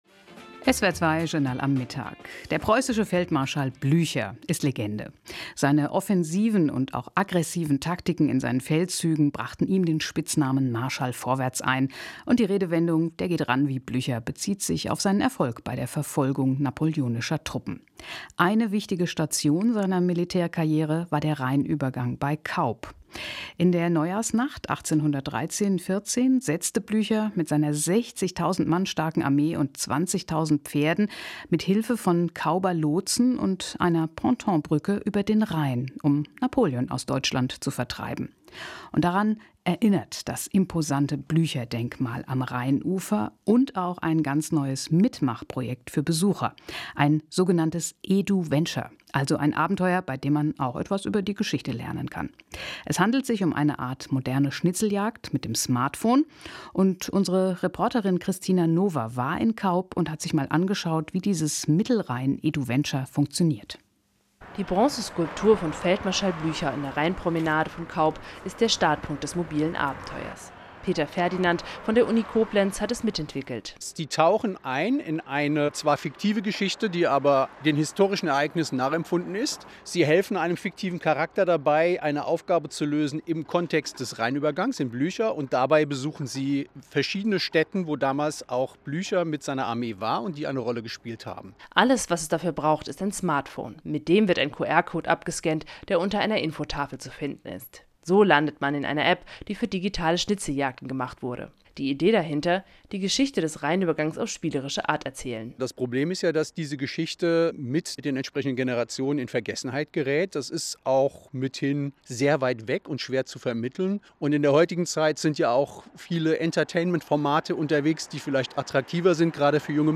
Bei der unterhaltsamen Exkursion entstand ein Beitrag für die Hörer*innen des Kulturradios SWR2, der am 25. Oktober in der Sendung „Journal am Mittag“ überregional ausgestrahlt wurde.